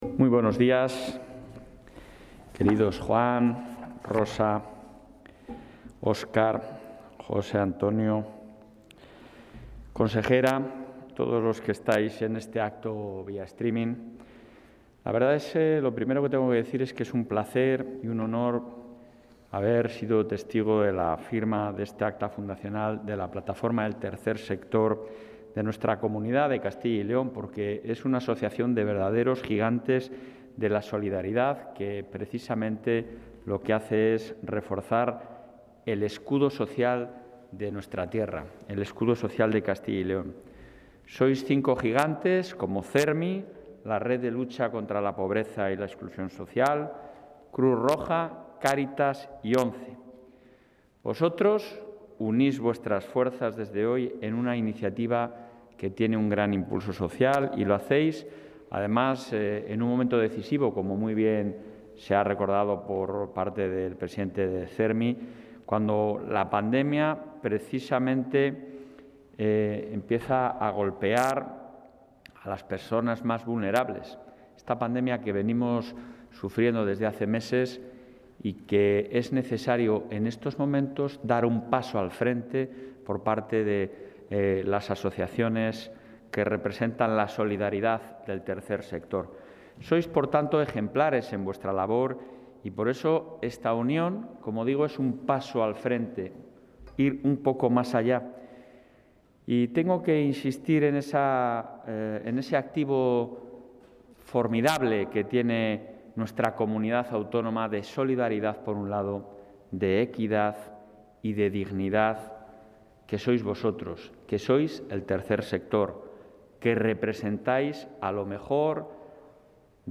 El presidente de la Junta de Castilla y León, Alfonso Fernández Mañueco, ha presidido esta mañana la constitución...
Intervención del presidente de la Junta.